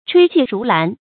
吹氣如蘭 注音： ㄔㄨㄟ ㄑㄧˋ ㄖㄨˊ ㄌㄢˊ 讀音讀法： 意思解釋： 見「吹氣勝蘭」。